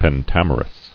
[pen·tam·er·ous]